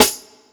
Caviar Snare.wav